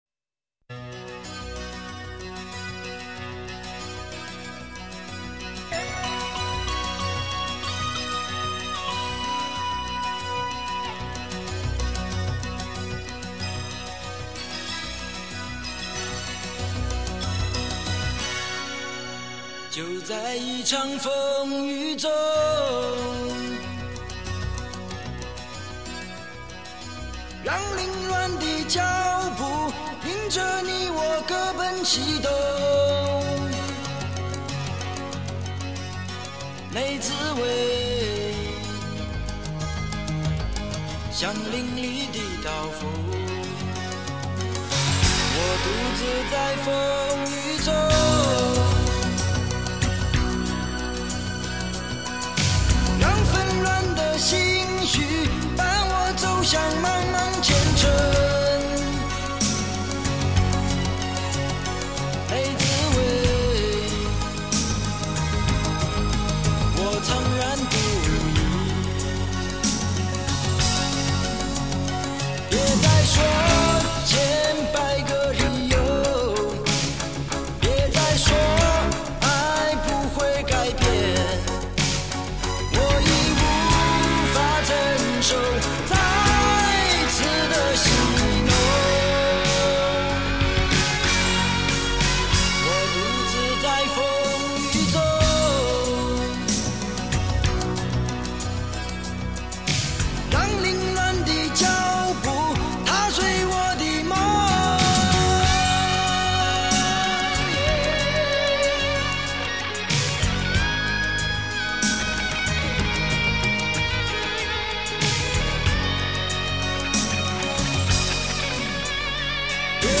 他的声音，有如独行侠般孤独豪迈